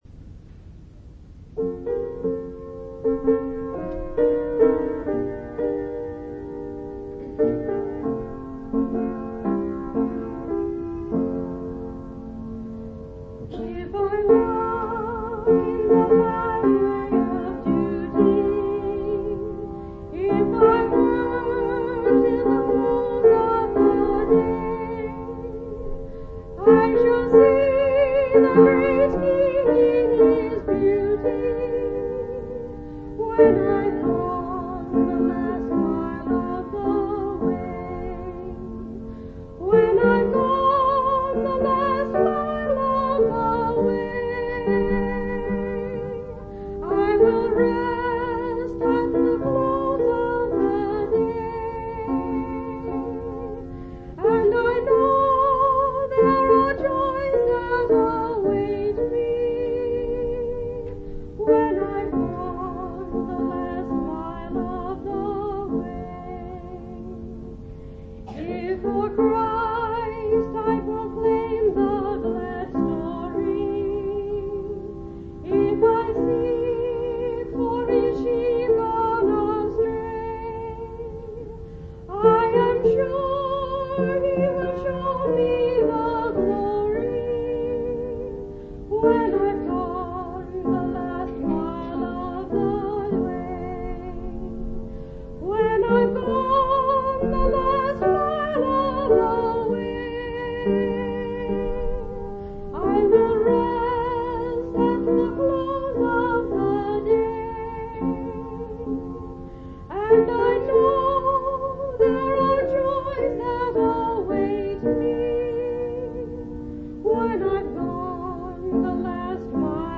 Missouri Reunion Event: Missouri Reunion